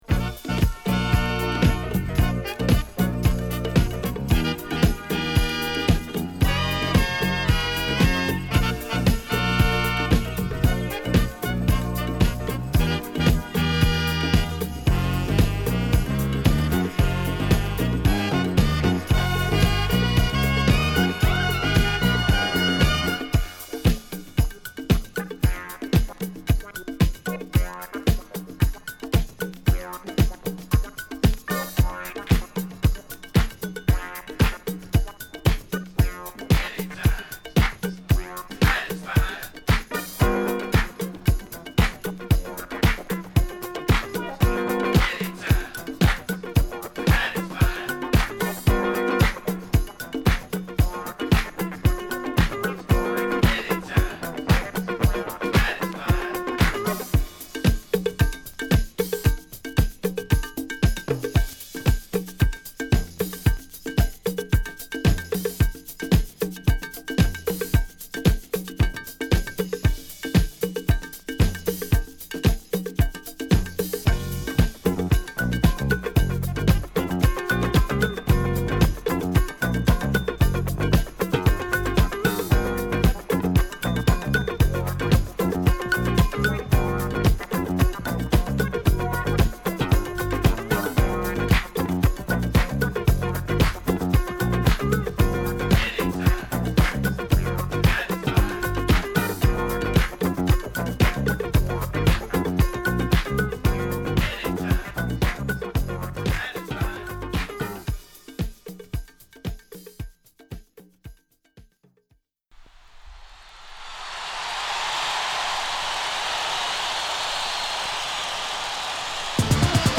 ＊A2からA3にかけて傷／プレイOKです。